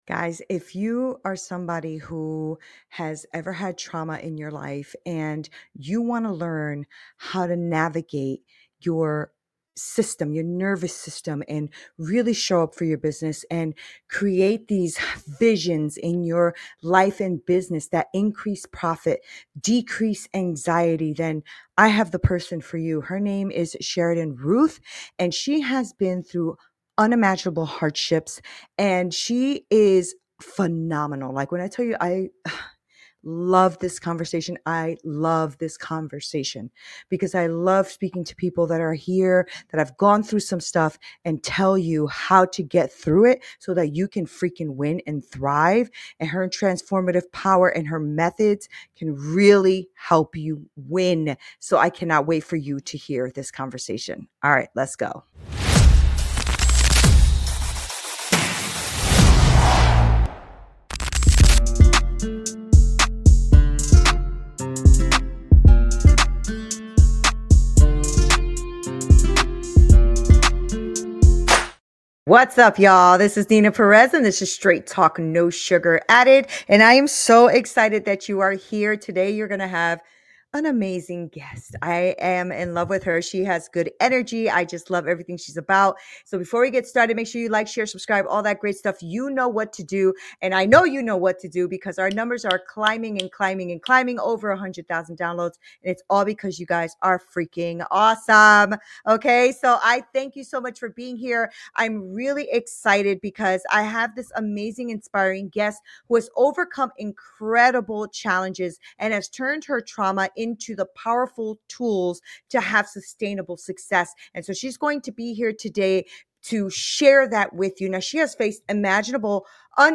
🌟 In this enlightening conversation, we explore how to increase profits, decrease anxiety, and build a life of impact.